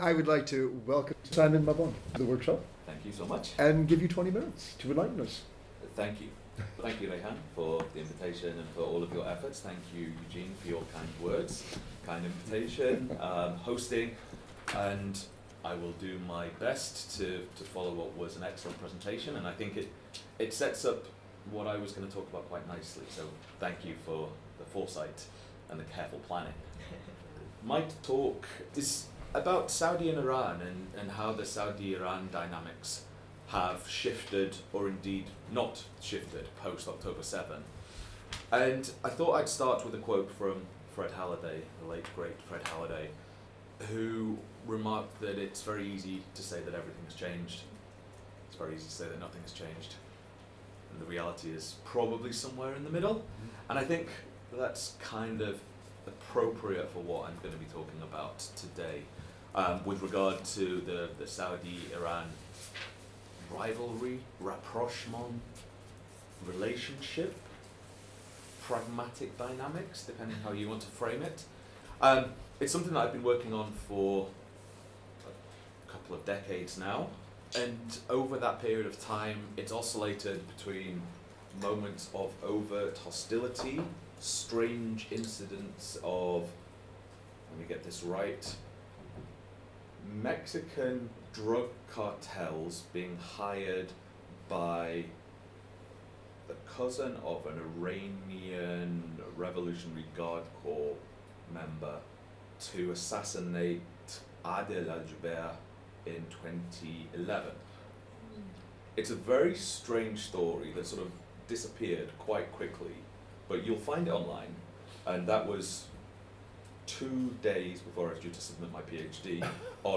Guest speaker contributing to the 1-day workshop: Transnational Islamic Movements: Global and Local Realities, held on Friday 29 November 2024.